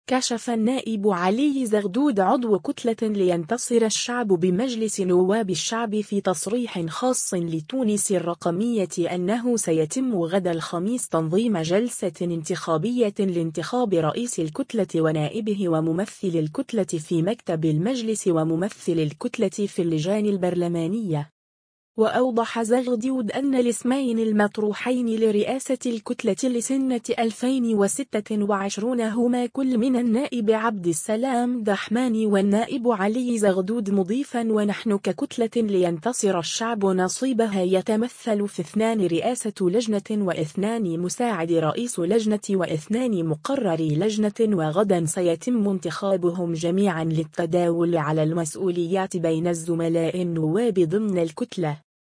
كشف النائب علي زغدود عضو كتلة لينتصر الشعب بمجلس نواب الشعب في تصريح خاص لـ”تونس الرقمية” أنه سيتم غدا الخميس تنظيم جلسة انتخابية لانتخاب رئيس الكتلة ونائبه وممثل الكتلة في مكتب المجلس وممثلي الكتلة في اللجان البرلمانية.